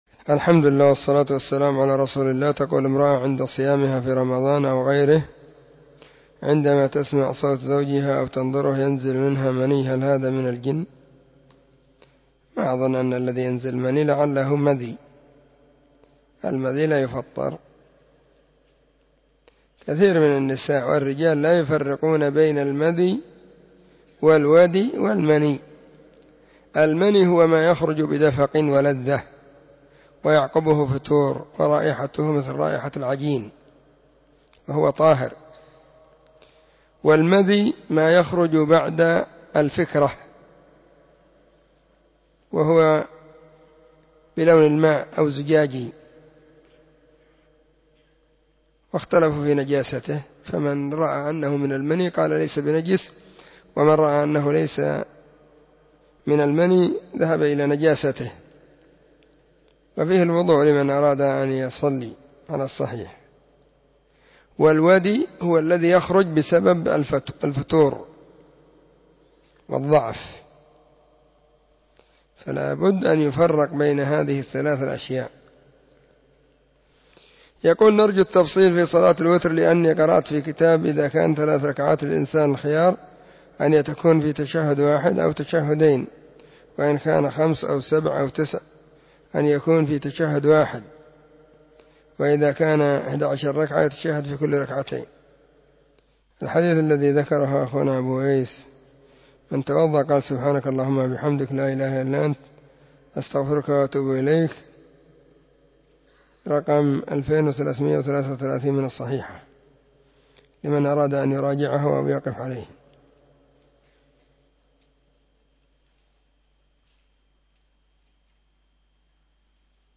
🔹 سلسلة الفتاوى الصوتية 🔸الاحد 17 /ذو القعدة/ 1442 هجرية.
الأحد 17 ذو القعدة 1442 هــــ | فتاوى مجموعة | شارك بتعليقك